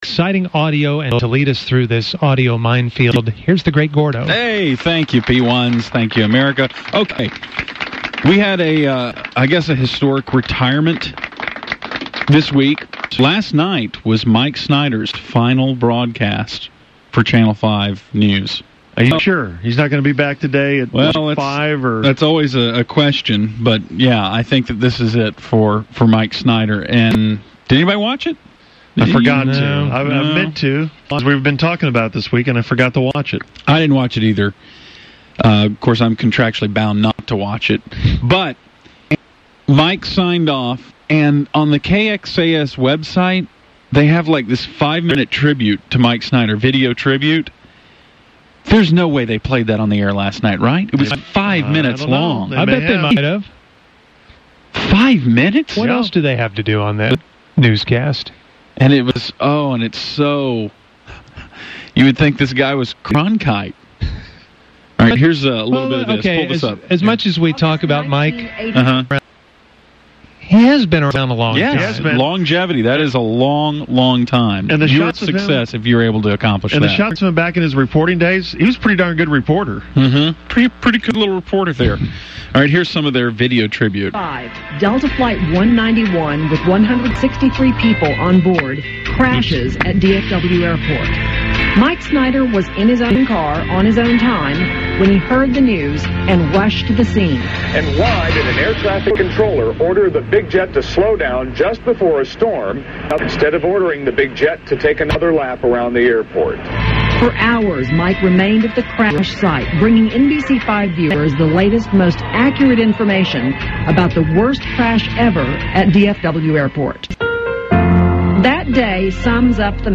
“Audio Minefield”. It consisted of a couple of audio gold moments, one from a local television station and one from the ticket.